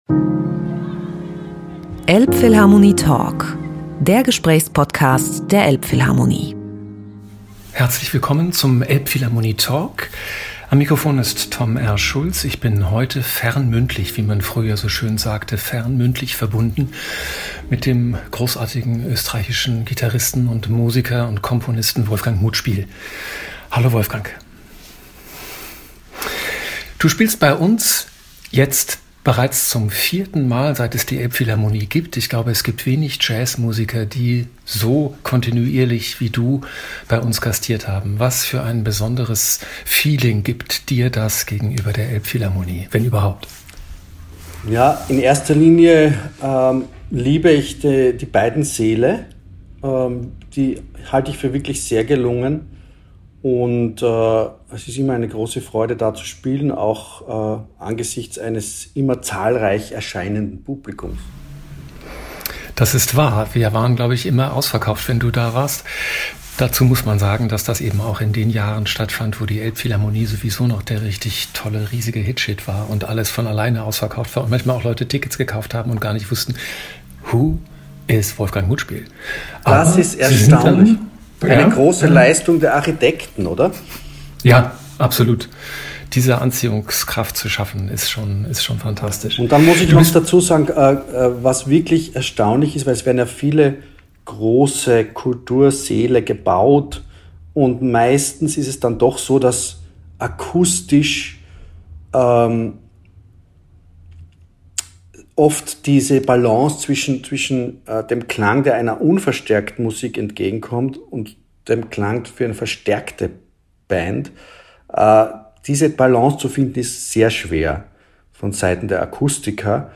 elbphilharmonie-talk-mit-wolfgang-muthspiel-mmp.mp3